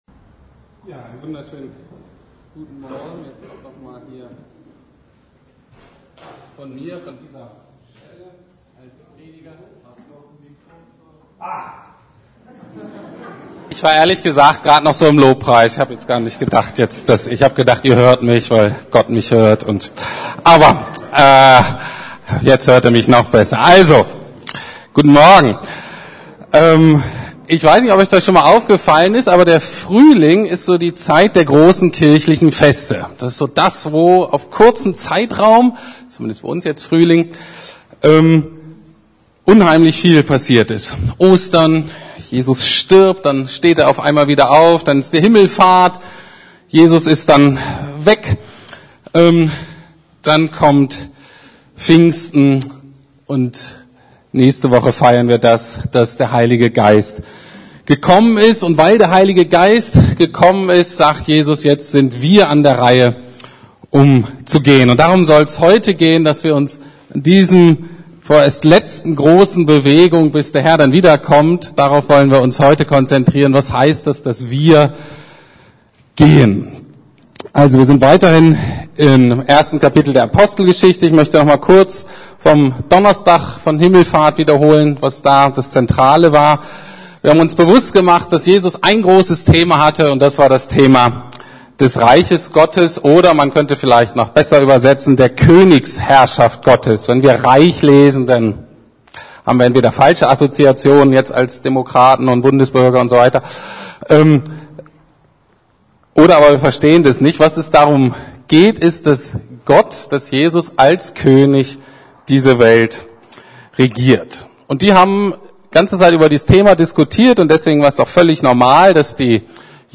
Himmelfahrt ~ Predigten der LUKAS GEMEINDE Podcast